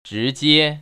[zhíjiē] 즈지에  ▶